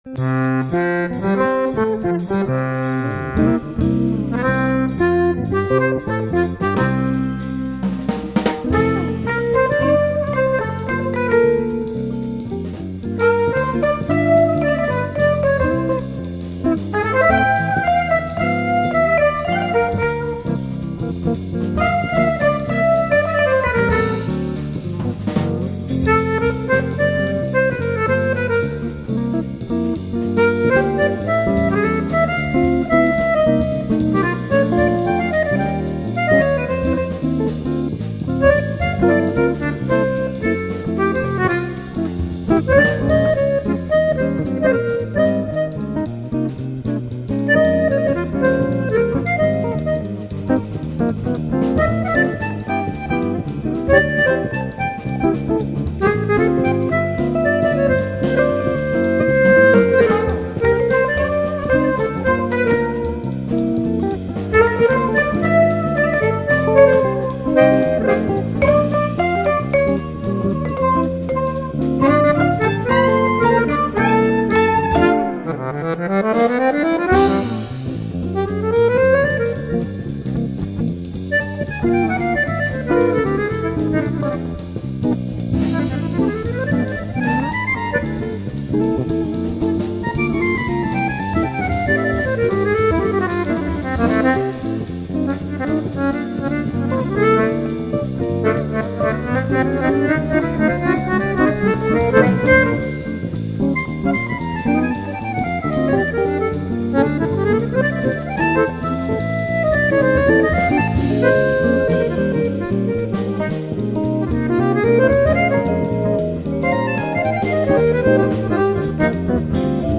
音乐类型: 民乐
Bossa nova是轻巧的游走，Funk是狂野的旋绕，Beguine是优雅的慢舞，Swing是自由的摇摆。
高音萨克斯风、Barython